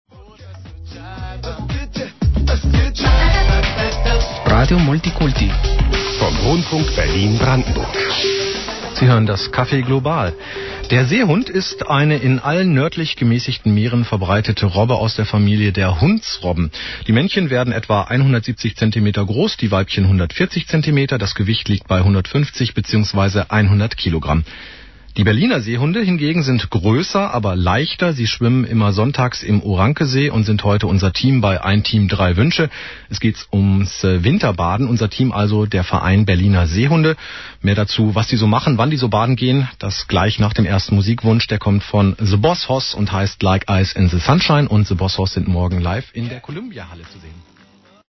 Anmoderation, mp3, 144 kB, 48sec